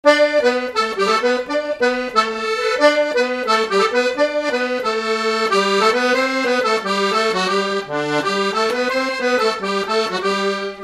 Grosbreuil ( Plus d'informations sur Wikipedia ) Vendée
Résumé instrumental
danse : scottish
Pièce musicale inédite